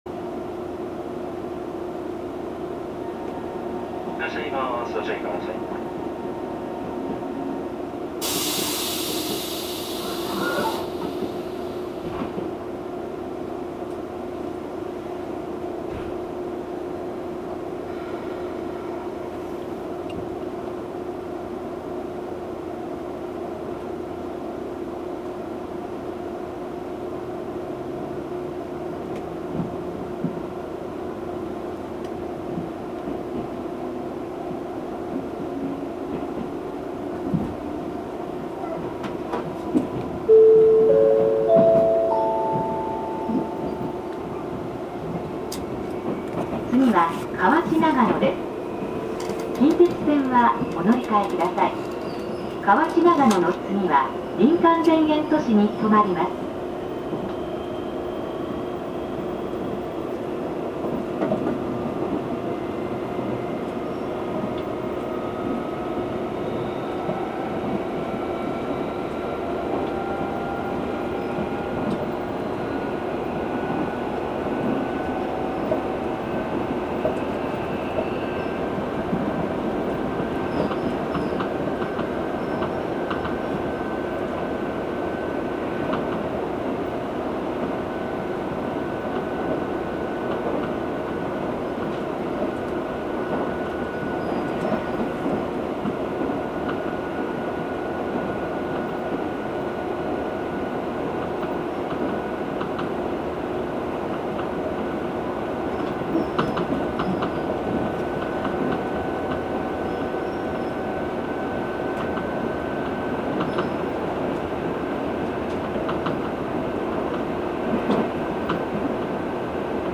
走行機器は抵抗制御で定格145kWのMB-3072-B形直流直巻モーターを制御します。
走行音
録音区間：金剛～河内長野(こうや1号)(お持ち帰り)